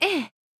casting.wav